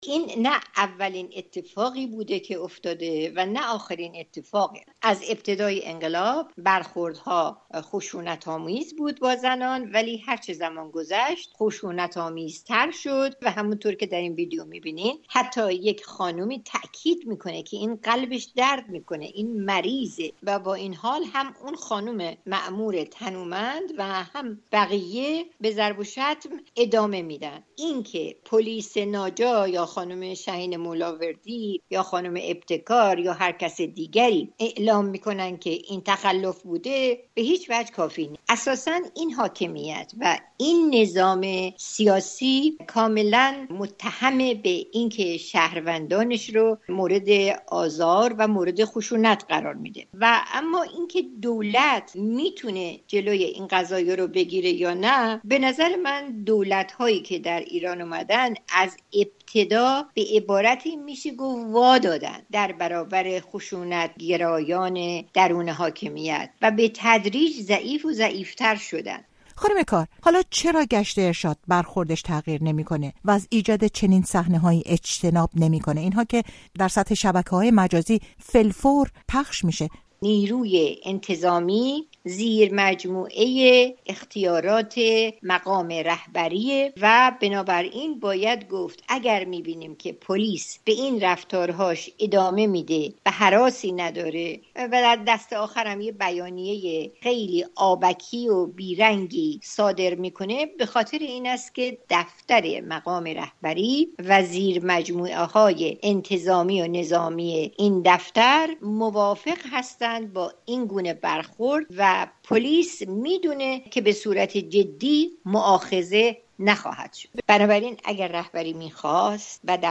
گفتگوی
انتشار ویدئوی کتک زدن یک دختر توسط مأموران «گشت ارشاد» با واکنش چند تن از مقام‌های جمهوری اسلامی روبه‌رو شده و موجی از واکنش‌ها را در شبکه‌های اجتماعی در پی داشته است. گفتگوی رادیو فردا با مهرانگیز کار،حقوقدان مقیم آمریکا: